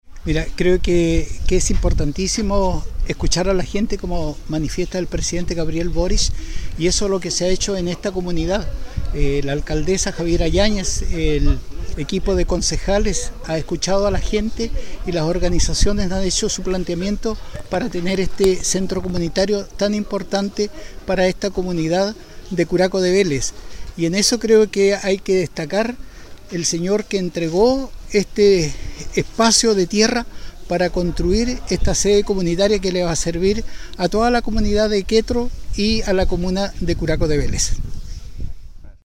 En esta misma línea, el delegado presidencial de Chiloé, Armando Barría, señaló: